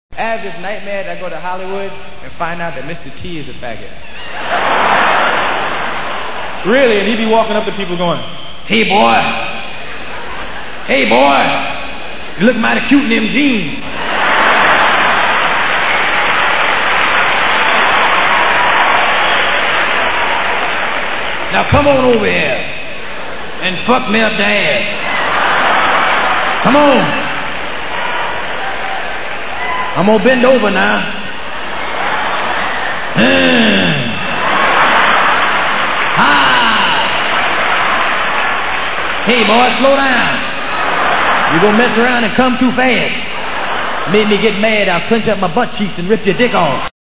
Delirious  Eddie talking about Mr. T (of A-Team fame) in Delirious (.Wav)